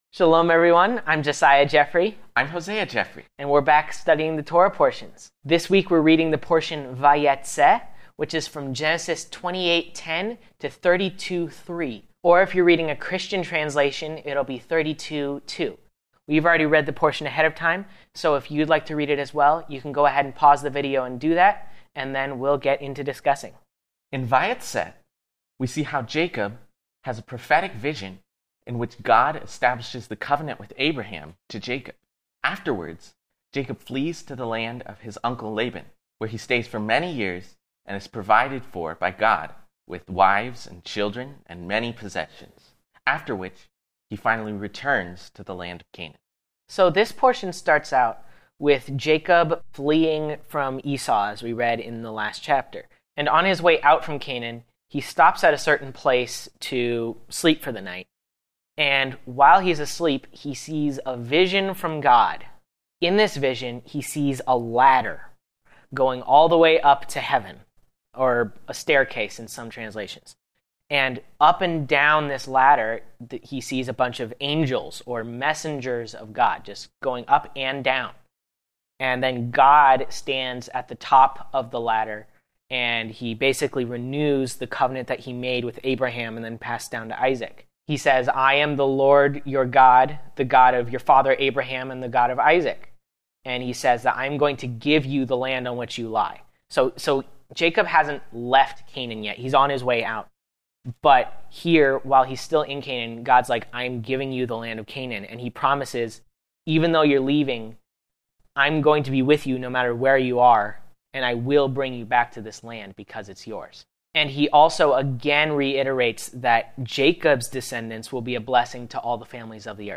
In this week’s Messianic Jewish Bible study